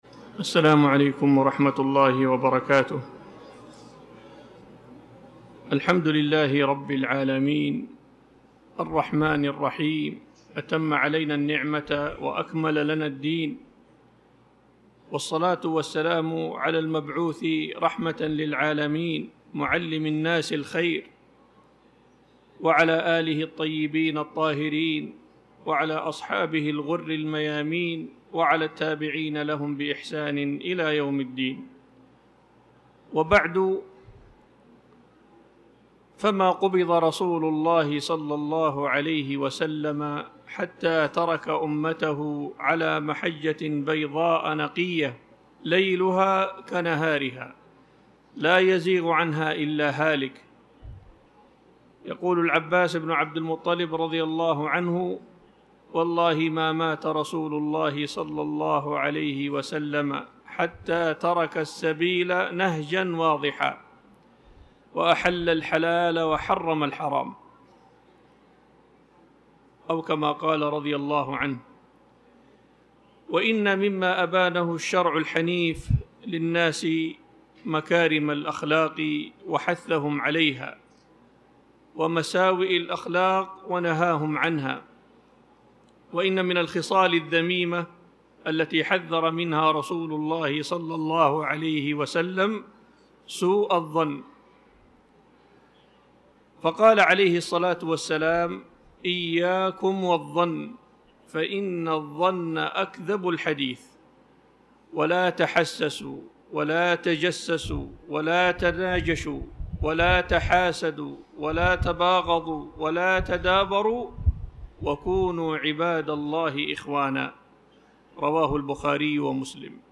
تاريخ النشر ٢٥ رجب ١٤٣٩ المكان: المسجد الحرام الشيخ
25رجب-محاضرة-إياكم-والظن1.mp3